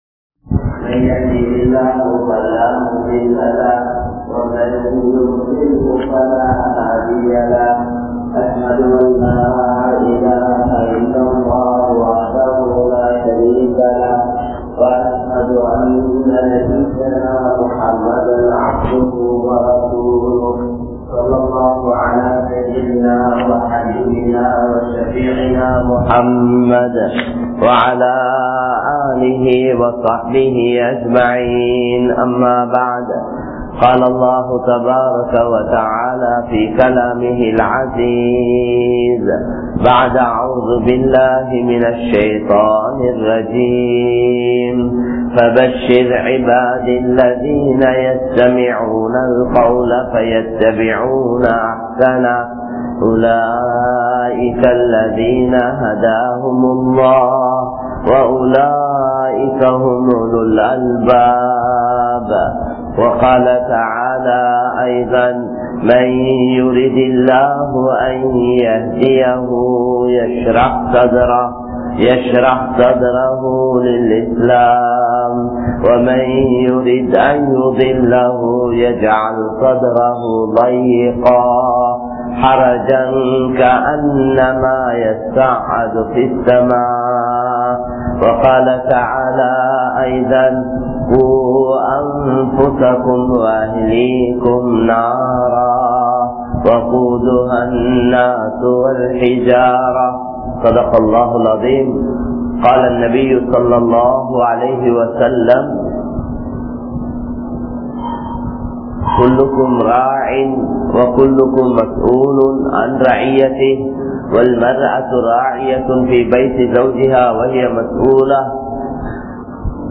Allah`vukkaaha Vaalungal (அல்லாஹ்வுக்காக வாழுங்கள்) | Audio Bayans | All Ceylon Muslim Youth Community | Addalaichenai
Muhaiyadeen Masjidh